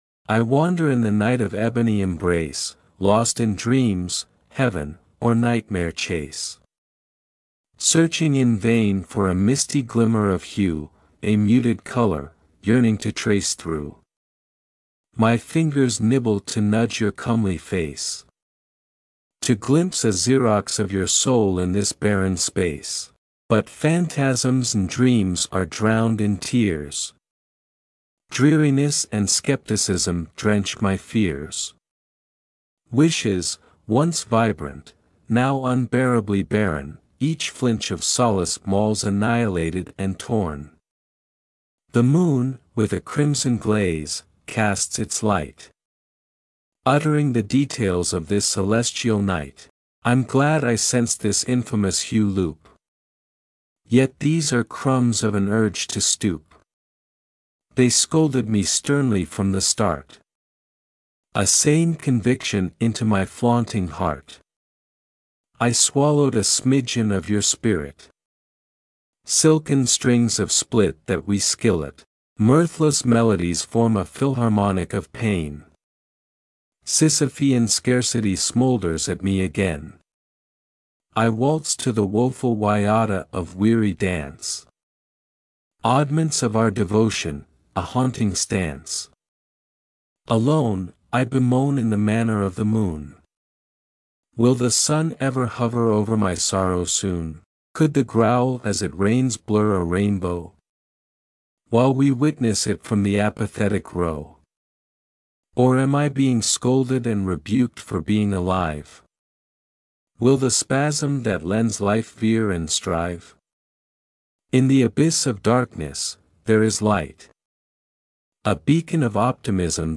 Again beautiful, I read, then listened, I like to do that before listening to the music, but you made a good choice it goes together.